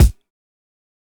BattleCatKick3.wav